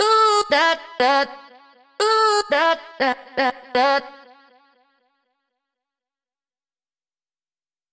Chops – 120BPM – 01
Unison-Chops-120bpm-01-F-Minor-A-Major-WET.mp3